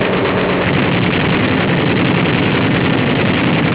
gunfireOLD.wav